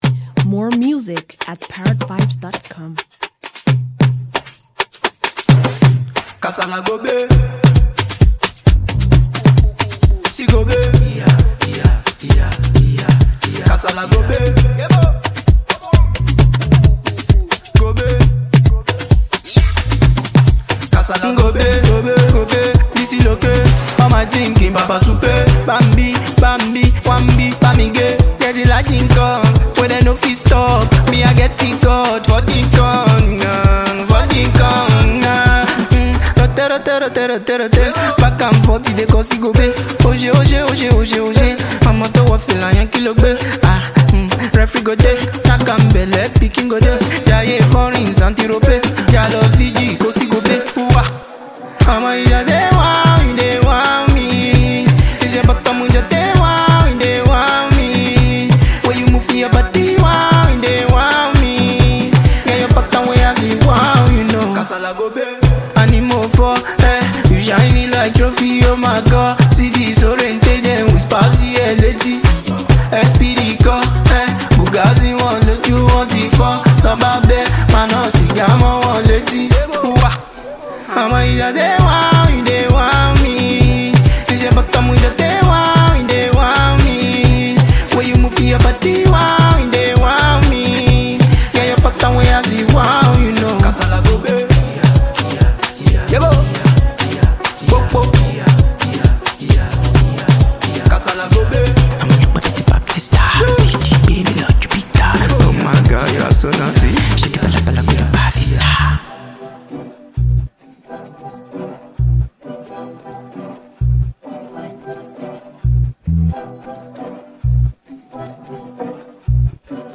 Inventively gifted Nigerian singer-songwriter and performer